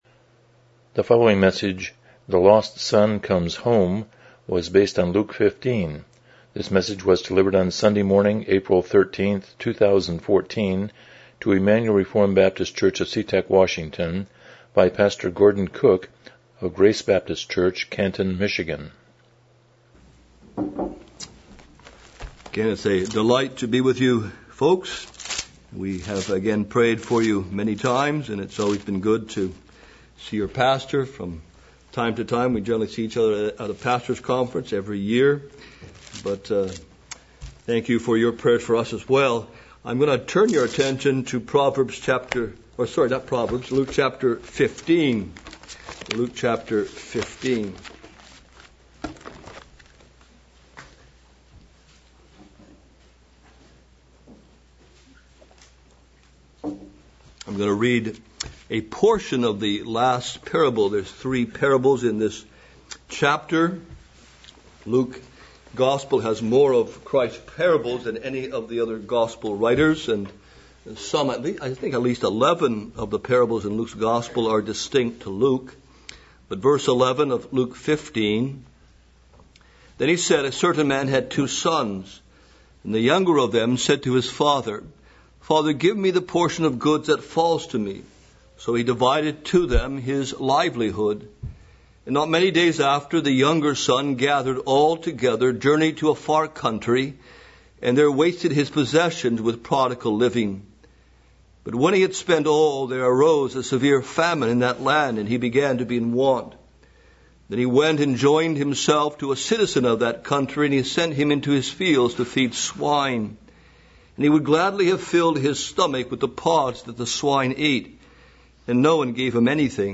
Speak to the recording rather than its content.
Passage: Luke 15:11-24 Service Type: Morning Worship